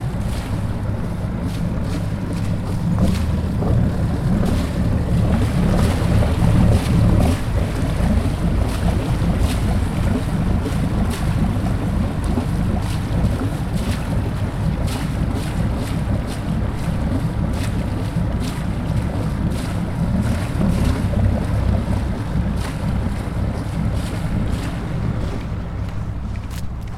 small fishing boat motor near a harbor.ogg
Original creative-commons licensed sounds for DJ's and music producers, recorded with high quality studio microphones.
small_fishing_boat_motor_near_a_harbor_b3m.mp3